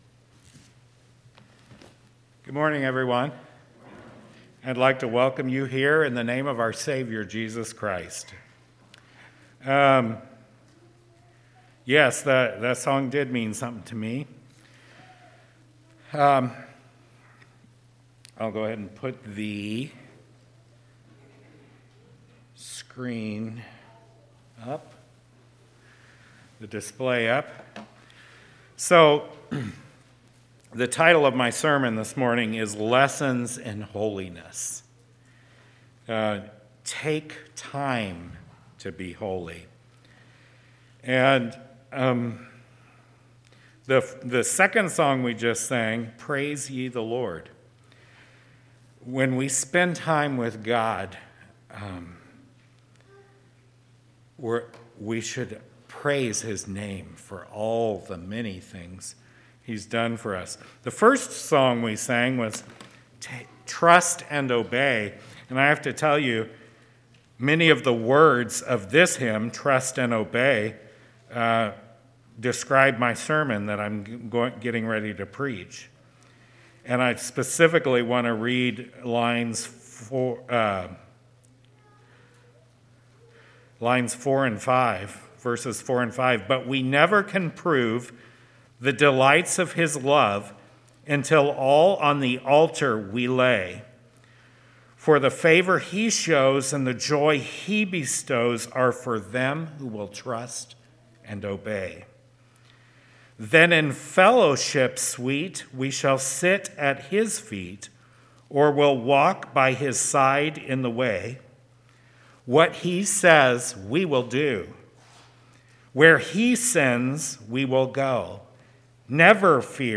1/31/2021 Location: Temple Lot Local Event